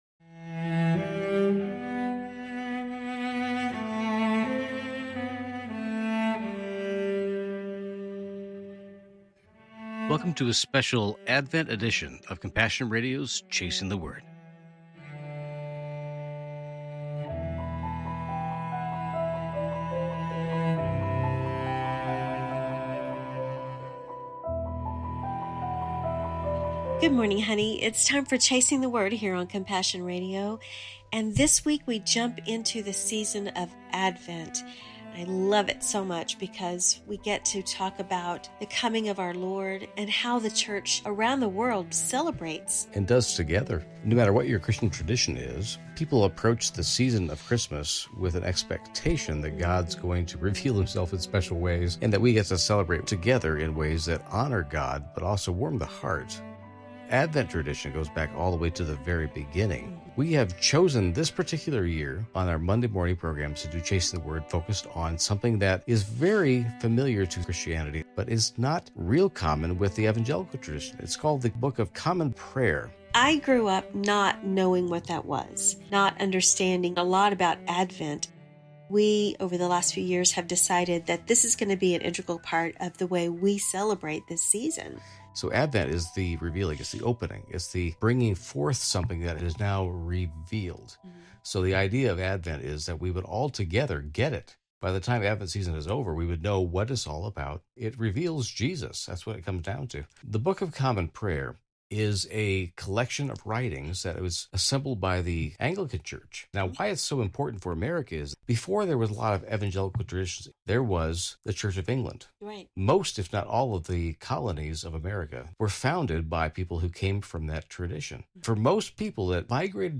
Immerse yourself in the action as Key Characters in the Redemption Story come to life through dramatic readings.